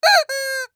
Rubber Chicken Squeak Toy 1 Sound Button - Free Download & Play